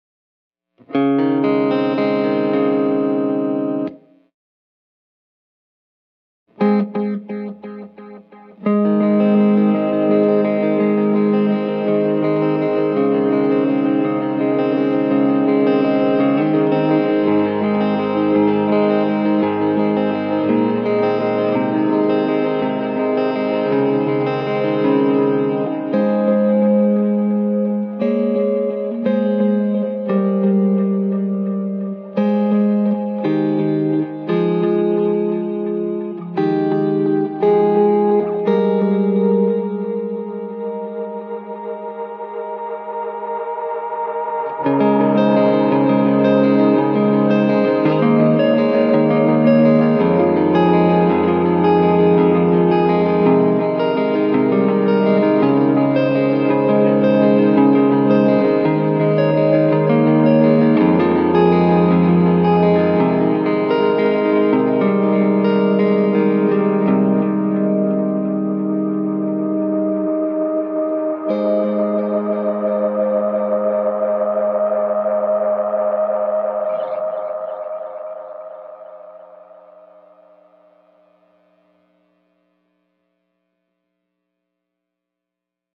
Clear, lush and percussive repeats frequently sent into swirling oscillation by MiniSwitch (set to Infinite Mode).